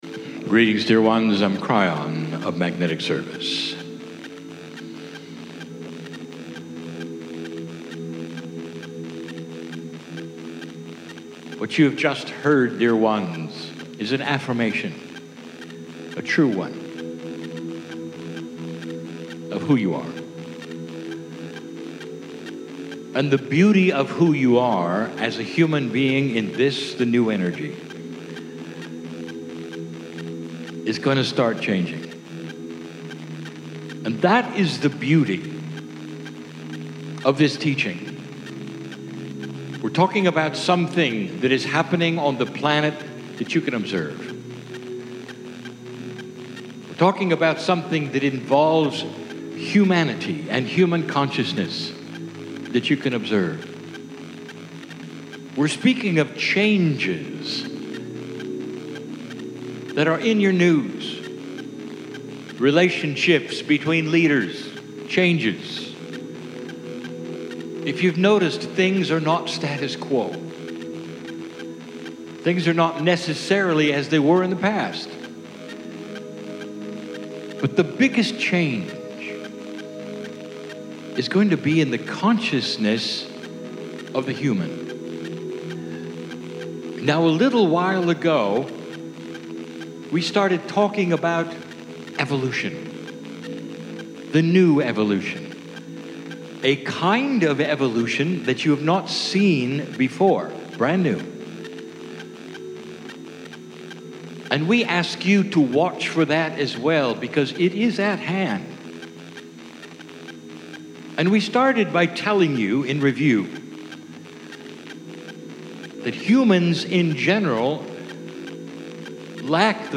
Alas, we can't control every audio system that we encouter around the planet, and the recording here in Bath was filled with digital noise that we couldn't remove.